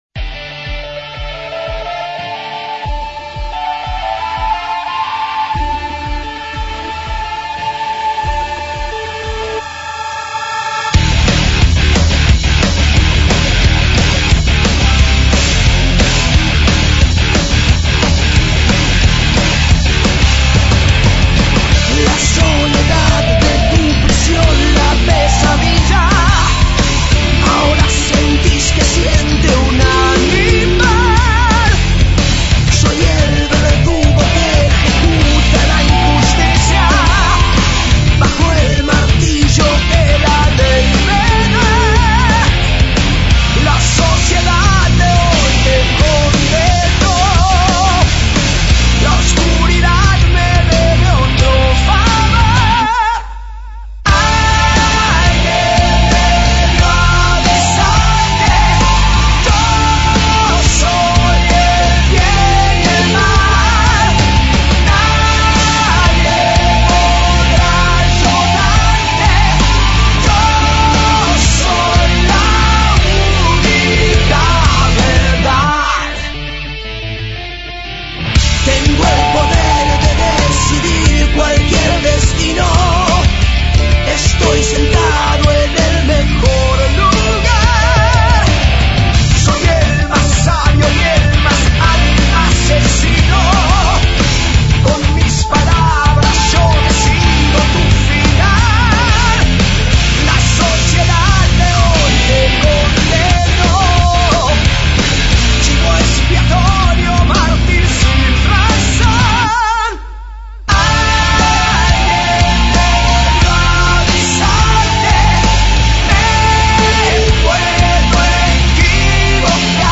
Rockzero
guitarra
bajo
batería